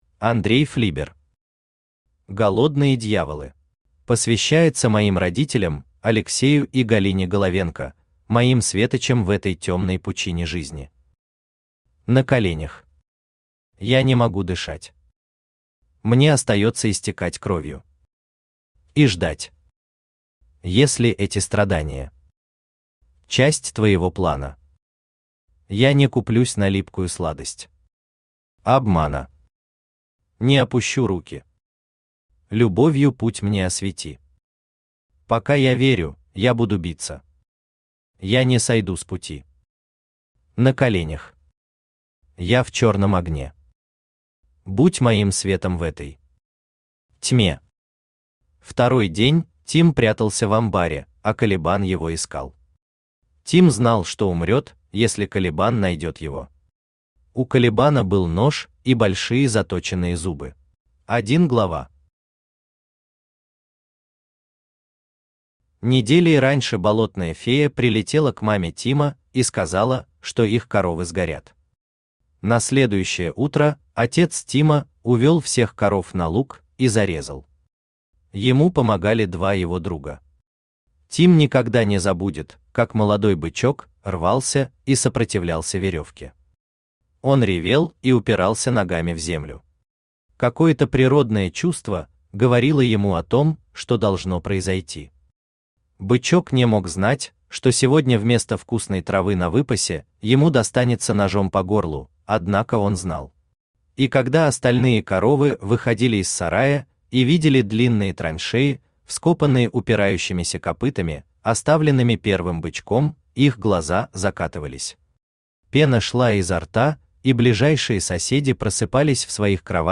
Аудиокнига Голодные дьяволы | Библиотека аудиокниг
Aудиокнига Голодные дьяволы Автор Андрей Флибер Читает аудиокнигу Авточтец ЛитРес.